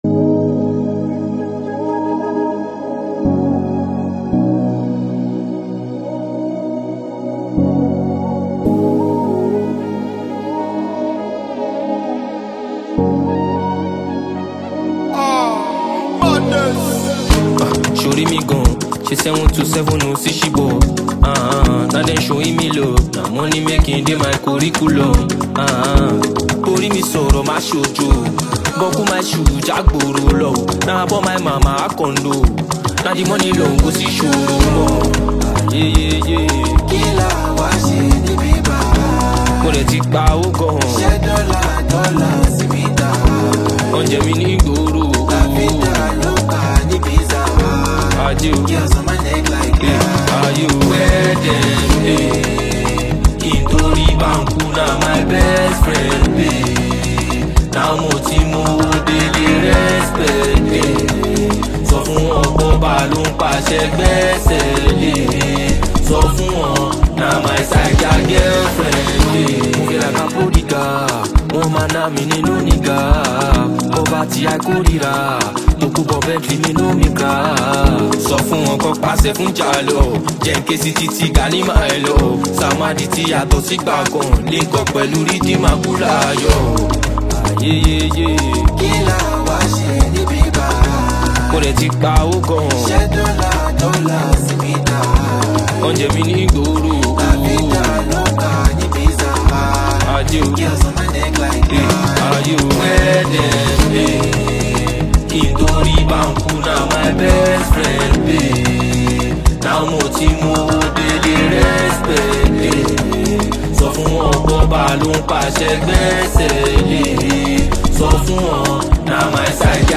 A terse, pointed cut with a street-level edge